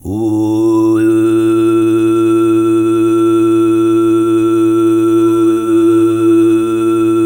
TUV2 DRONE06.wav